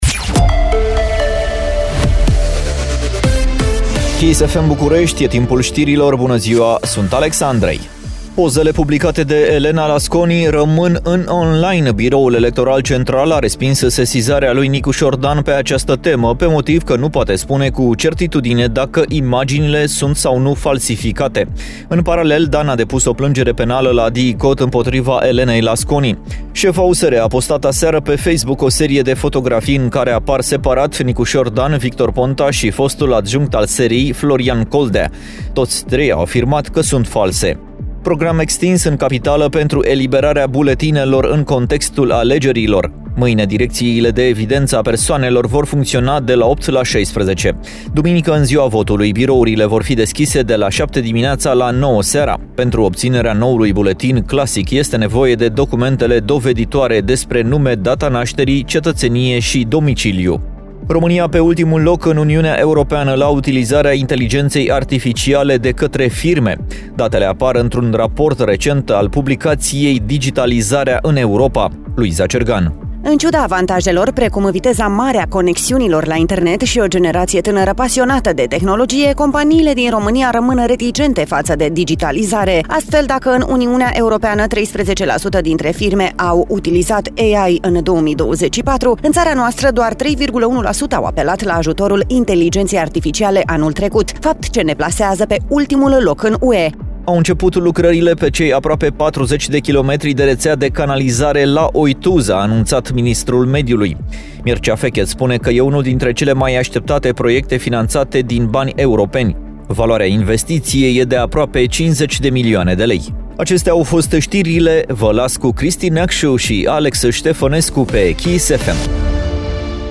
Știrile zilei de la Kiss FM